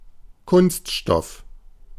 Ääntäminen
Synonyymit matière plastique Ääntäminen France: IPA: [plas.tik] Haettu sana löytyi näillä lähdekielillä: ranska Käännös Ääninäyte Substantiivit 1.